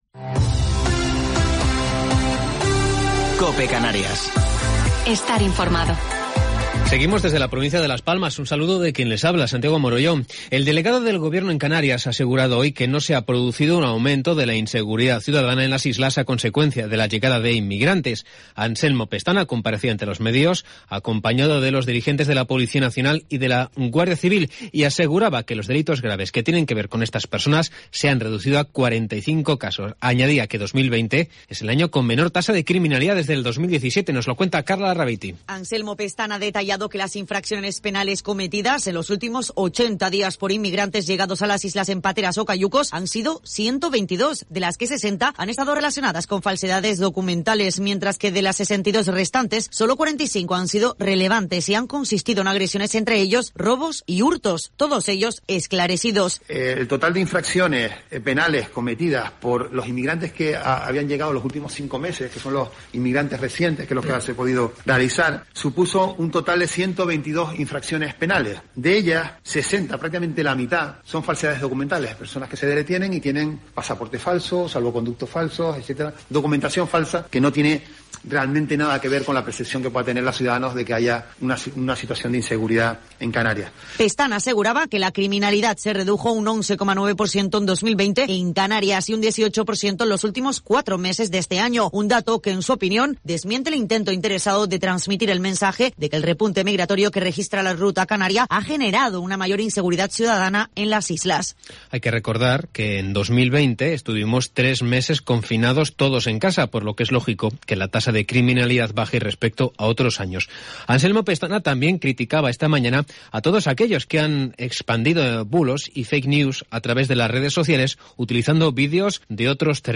Informativo local 4 de Febrero del 2021